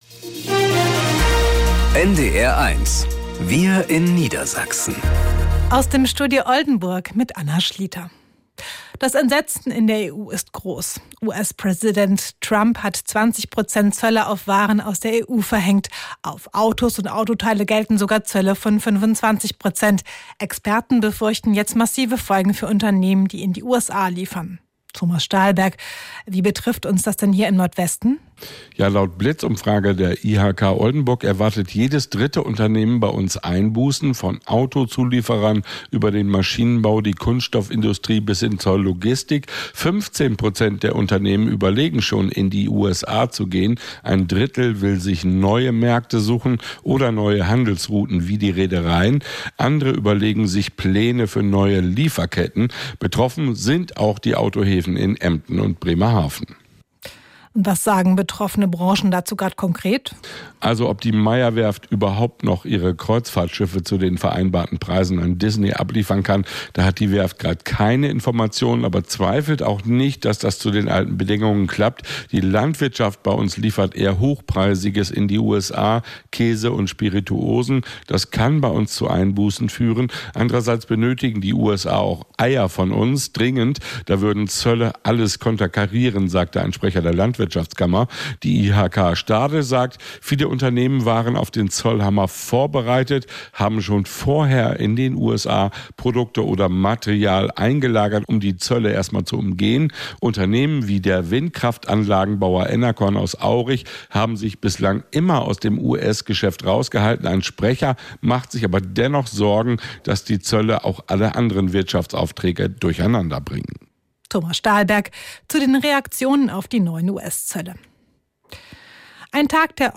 Wir in Niedersachsen - aus dem Studio Oldenburg | Nachrichten